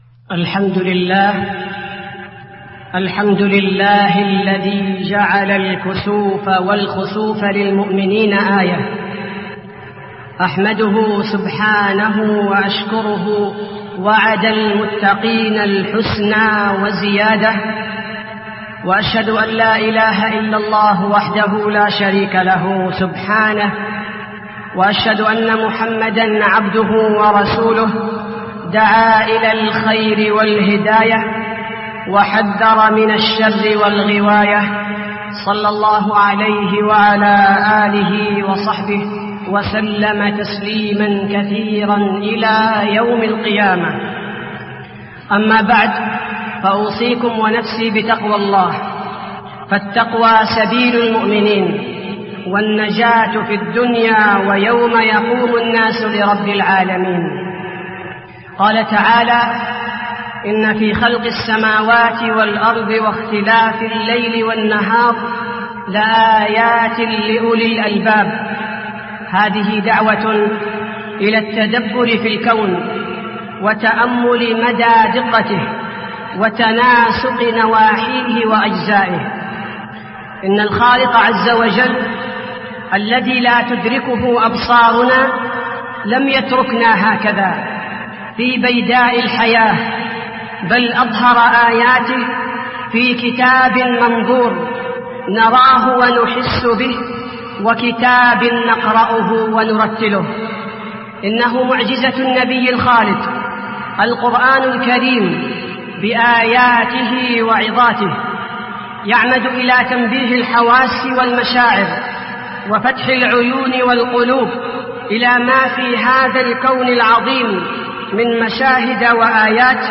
خطبة الكسوف المدينة - الشيخ عبدالباري الثبيتي
تاريخ النشر ٢٩ ربيع الثاني ١٤٢٠ هـ المكان: المسجد النبوي الشيخ: فضيلة الشيخ عبدالباري الثبيتي فضيلة الشيخ عبدالباري الثبيتي خطبة الكسوف المدينة - الشيخ عبدالباري الثبيتي